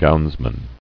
[gowns·man]